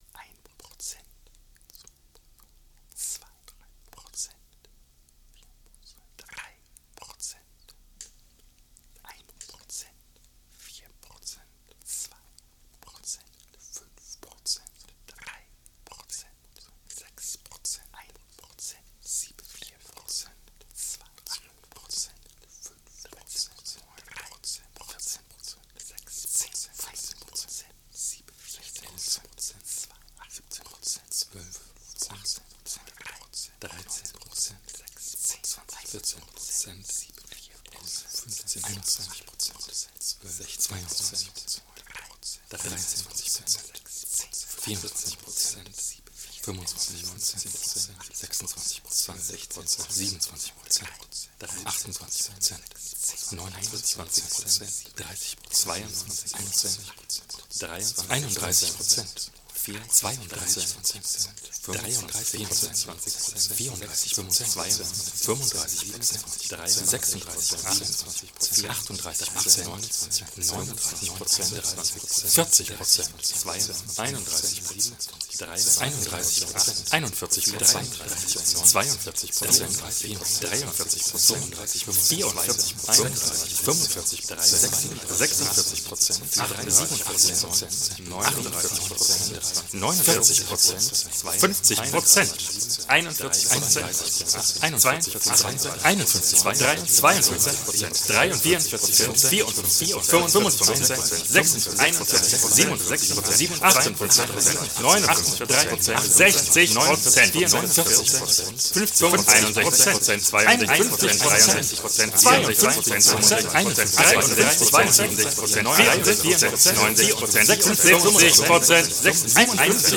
voice & liveloops
Rec. live to hard disc. No overdubs.